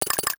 NOTIFICATION_Metal_02_mono.wav